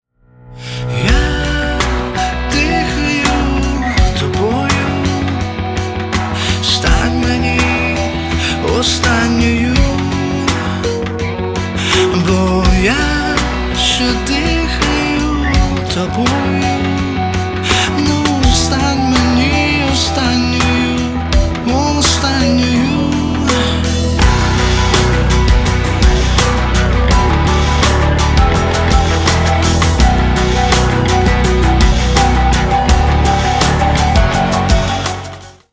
мужской вокал
красивые
поп-рок